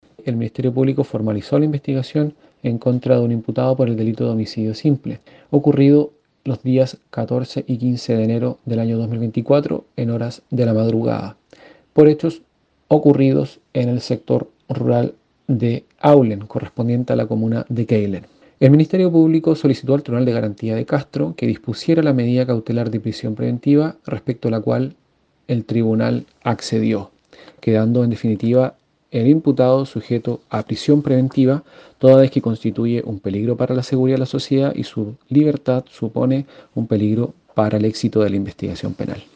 El fiscal (S) David Parrini Leal, de la fiscalía de Castro, manifestó que los hechos tuvieron lugar en la localidad rural de Áulen, en la comuna de Quéilen a comienzos de este año 2024.